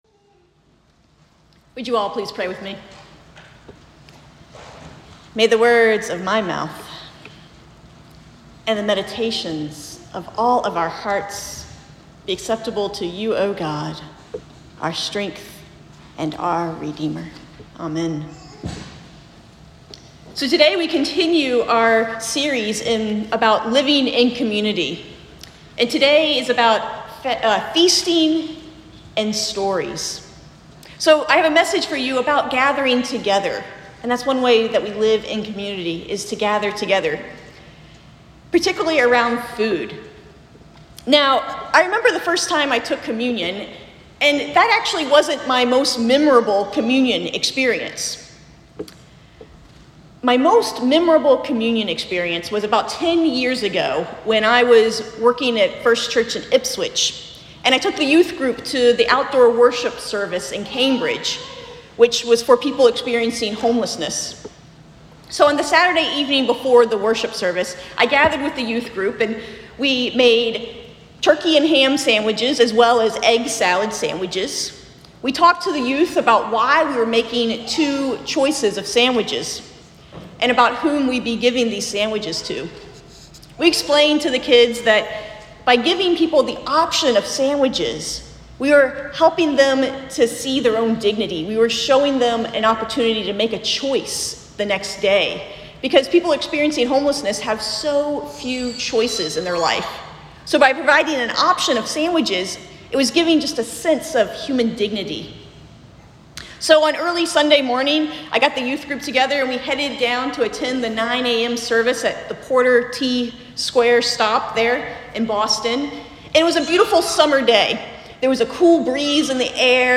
Feasting and Listening Sermon